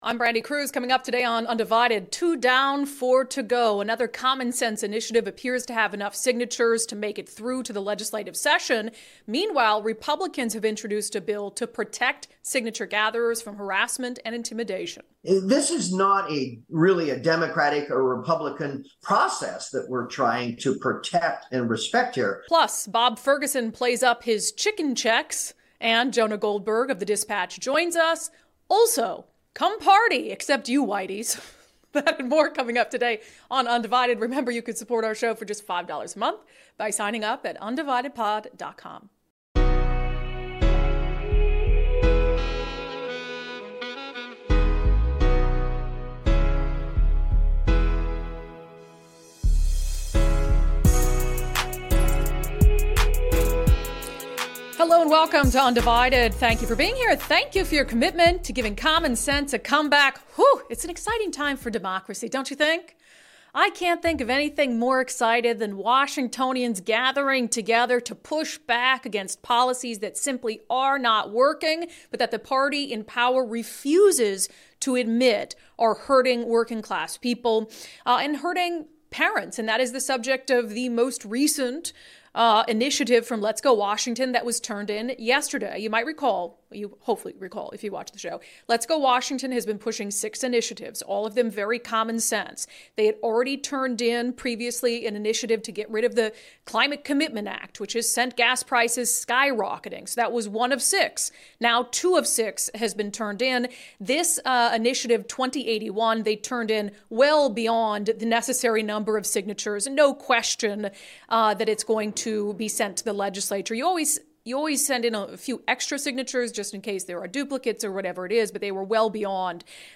A second common sense initiative appears to have the necessary signatures, can the rest make it in time? Republicans introduce bill to protect initiative process. Bob Ferguson plays up chicken checks. Jonah Goldberg of The Dispatch joins us.